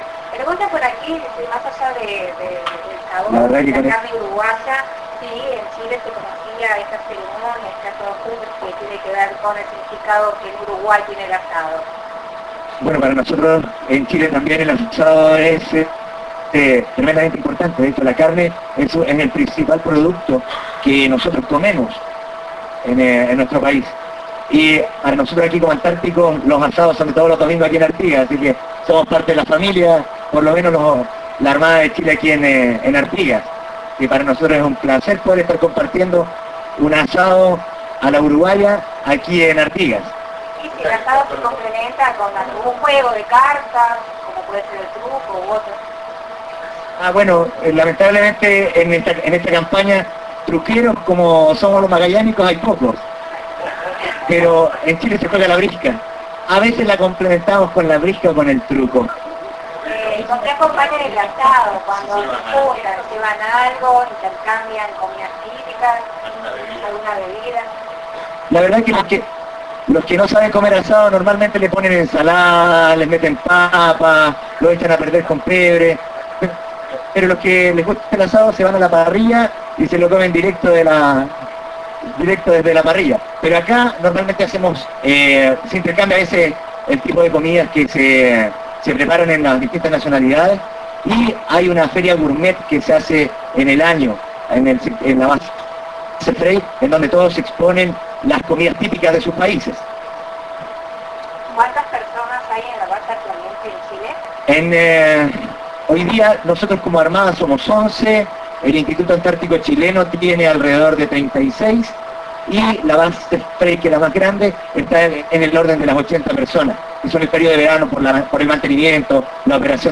Representante de estación de